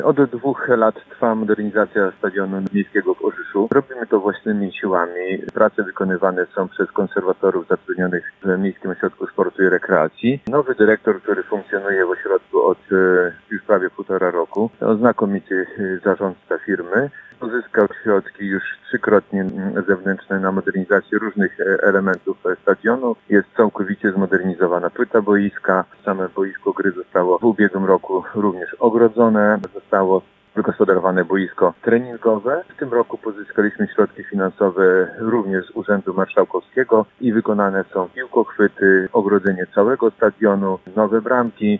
Jak mówi Zbigniew Włodkowski, burmistrz Orzysza, już zamontowane są piłkochwyty, wymienione są bramki oraz ogrodzenie obiektu.